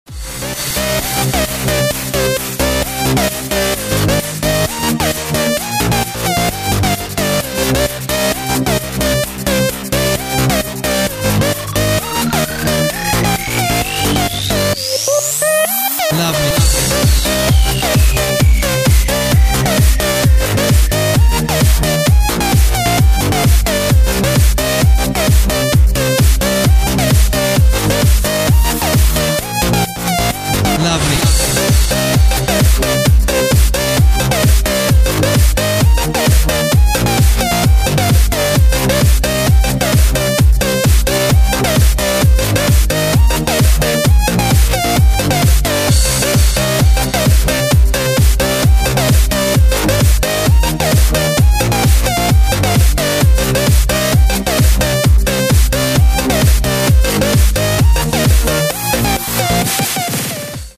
• Качество: 128, Stereo
мужской голос
громкие
dance
Electronic
EDM
электронная музыка
club
electro house